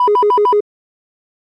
It kinda sounds like a phone ringing to me.
I keep hearing a certain sound especially in EDM lately which sounds like a distorted sine wave playing a b5 and G#4 in an arp.
I tried recreating it. It's not exact but it's close enough. 3e3a04da1ea18350.mp3 1+